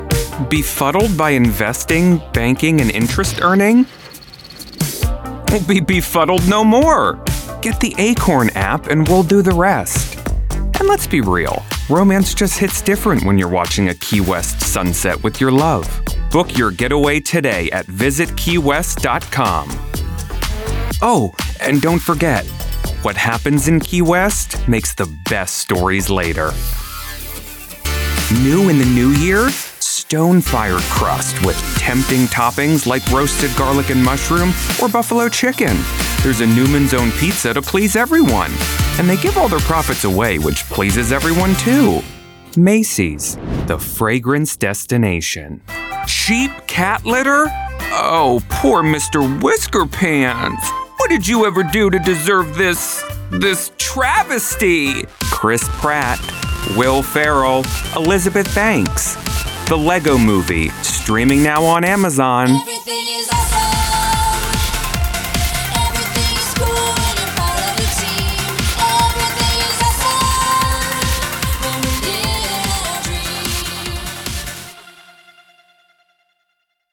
Commercial Demo Reel
Middle Aged
My reads land with confidence and playfulness.
Words used to describe my work -- approachable, trustworthy, fun, silly, camp, dramatic, youthful, befuddled, appealing, eloquent, playful, conversational, comedic, fresh, personable, bright, and mischievous.